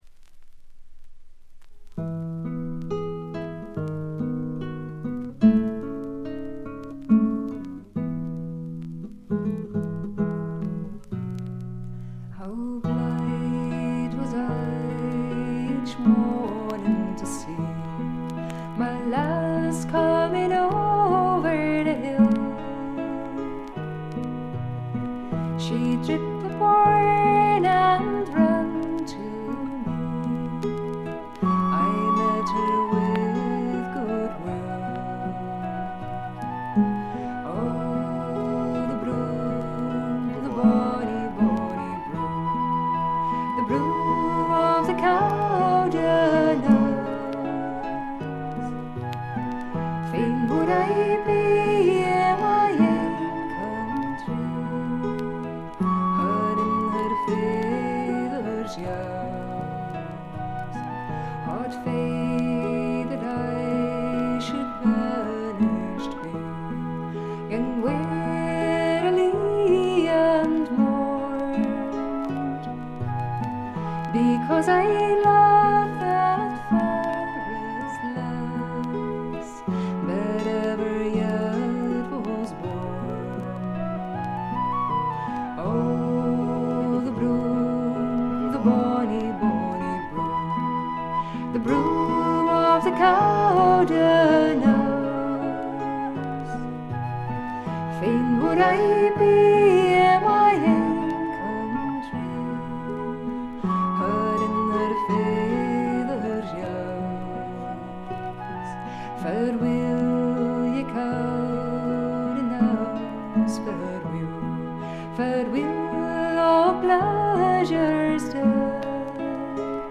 バックグラウンドノイズ、チリプチ多め大きめ。
オランダのトラッド・フォーク・グループ
試聴曲は現品からの取り込み音源です。